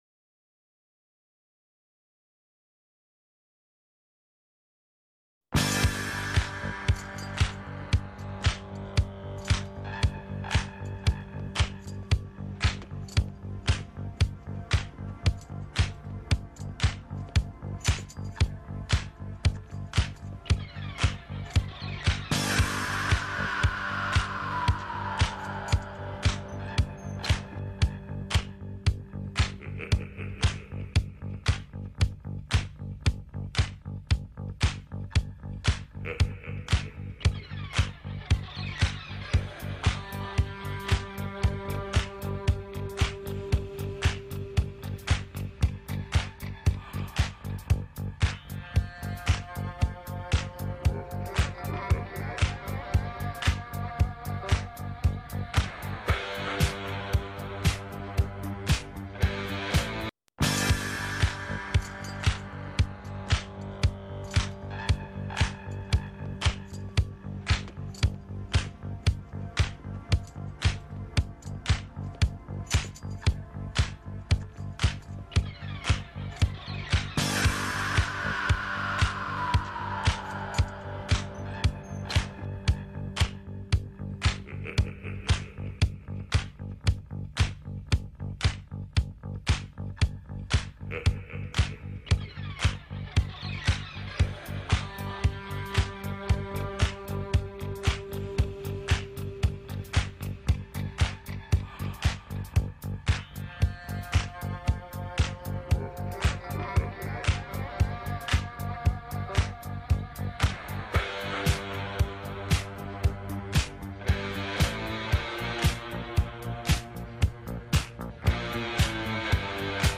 "C'est de la Locale" est une émission quotidienne diffusée en direct de 18 à 19h du lundi au vendredi. On y traite des infos associatives ou culturelles locales.